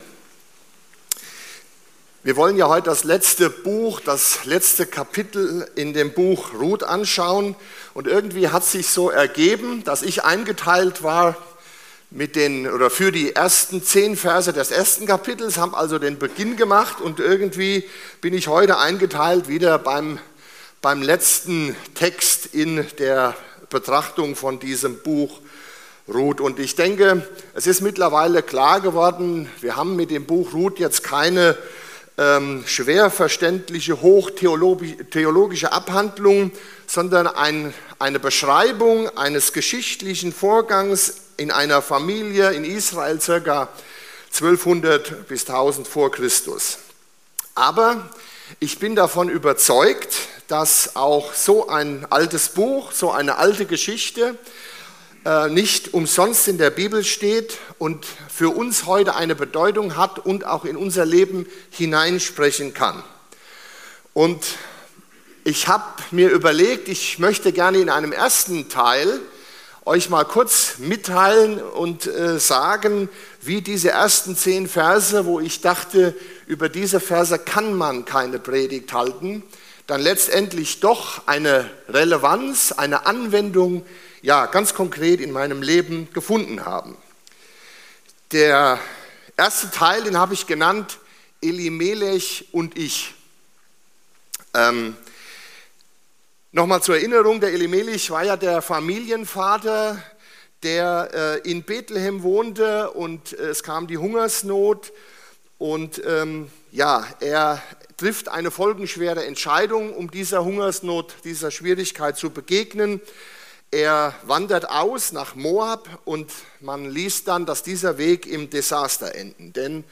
03.08.2025 Wortbetrachtung